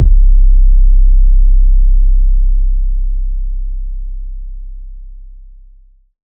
808 SOUTHSIDE (W KICK).wav